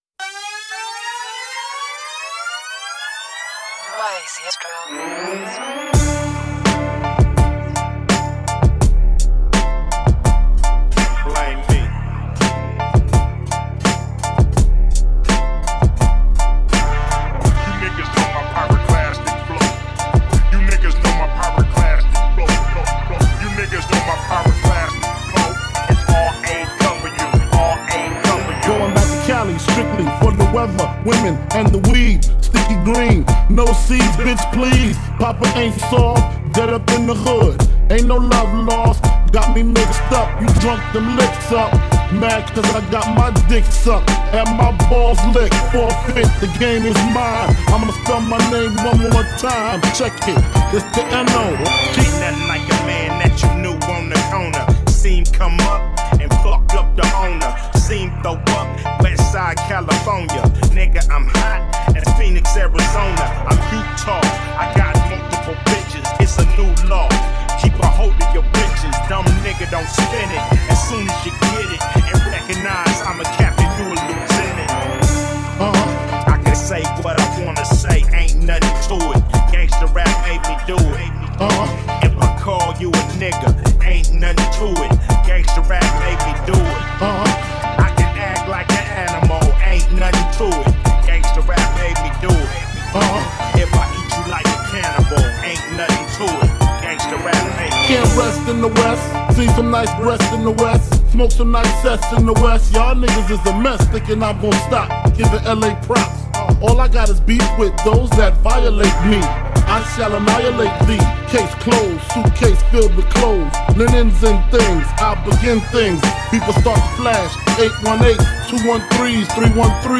a hiphop mashup
A couple accapellas and an instrumental.